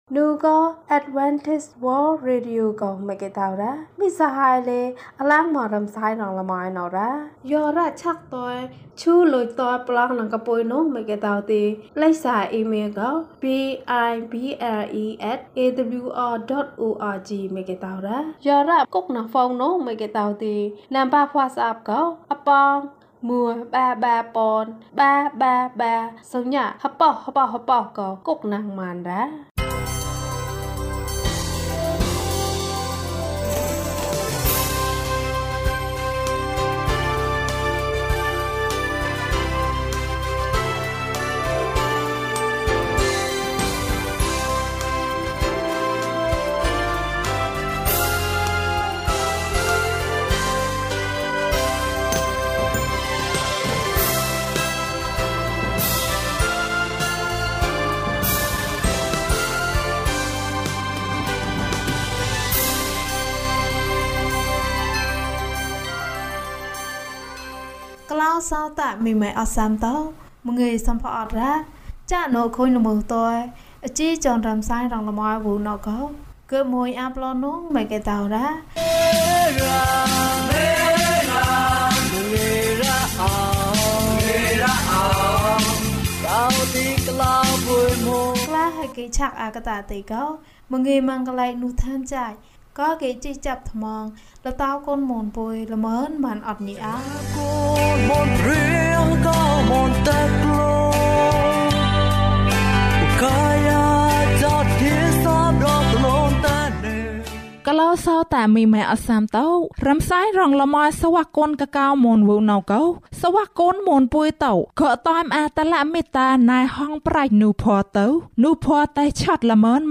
ချိုမြိန်သောနေ့၌။ ကျန်းမာခြင်းအကြောင်းအရာ။ ဓမ္မသီချင်း။ တရားဒေသနာ။